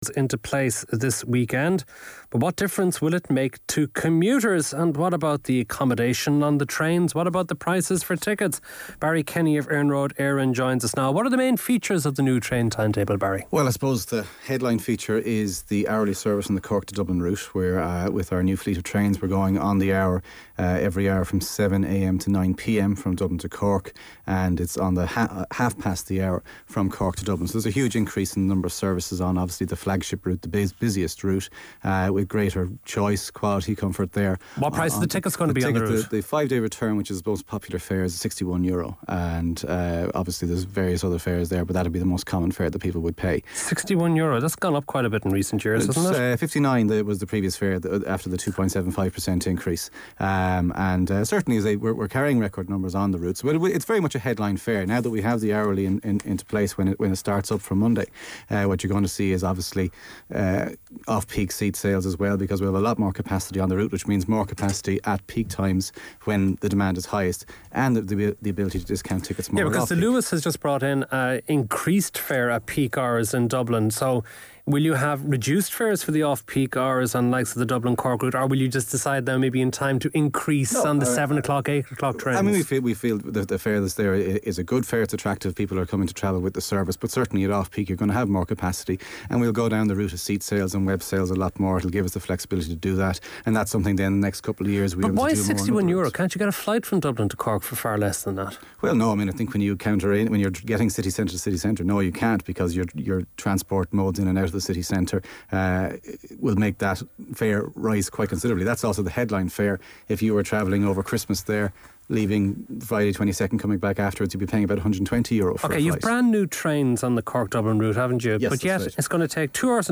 A interview on the Last Word Today FM following the publication of the 2007 list of best station awards.